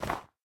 minecraft / sounds / step / snow4.ogg
snow4.ogg